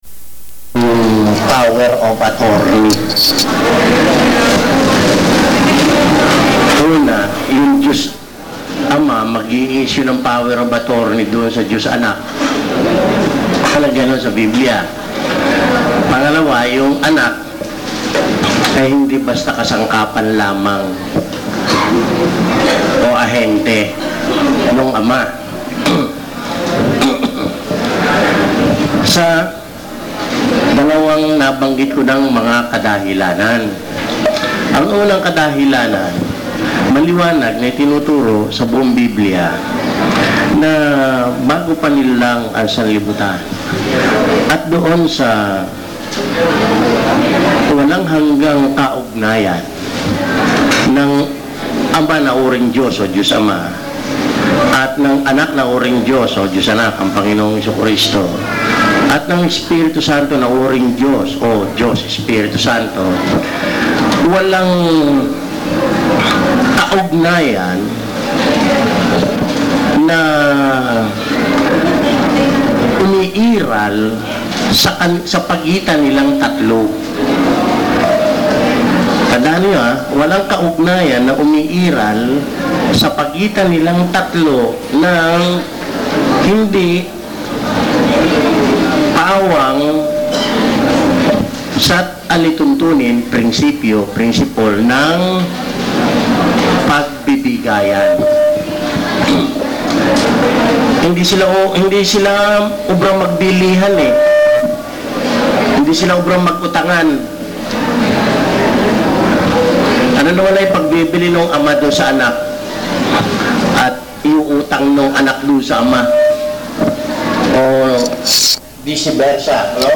Posted on March 9, 2015 in audio bible study, Bible study, Christianity, faith, God, JESUS CHRIST, lectures, religion, sermons, spirituality